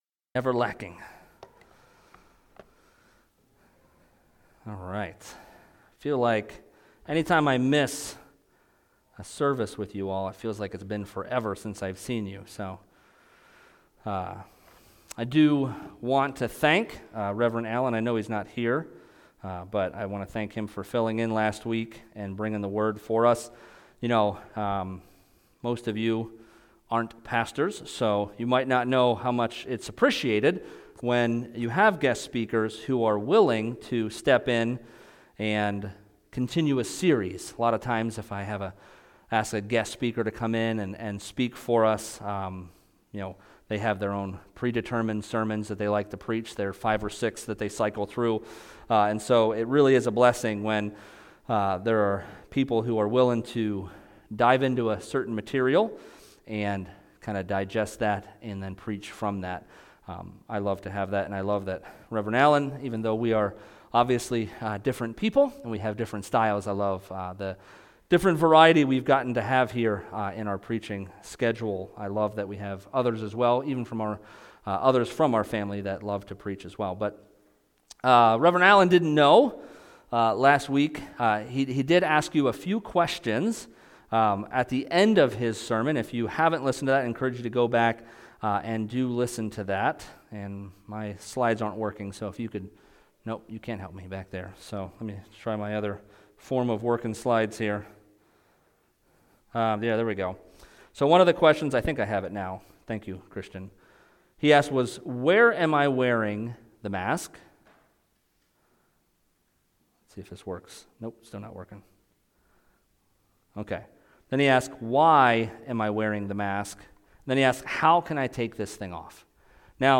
Sermons | DuBois Alliance